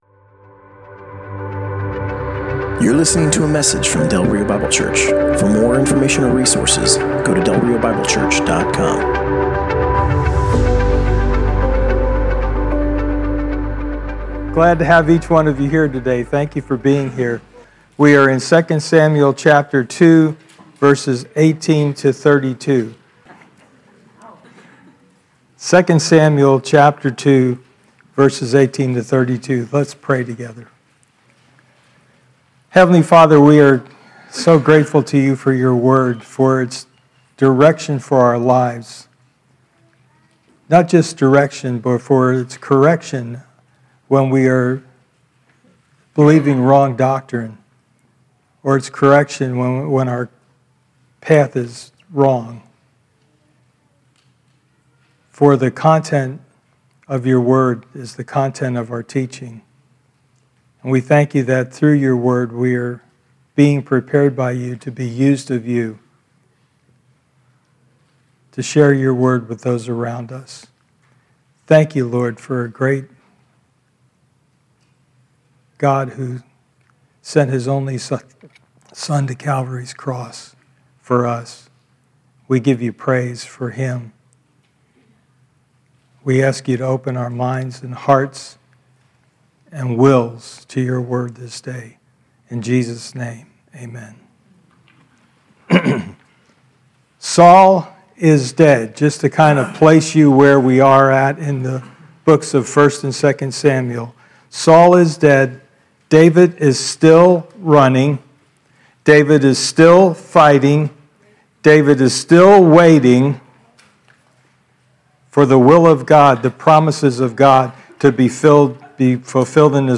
Passage: 2 Samuel 2: 18-32 Service Type: Sunday Morning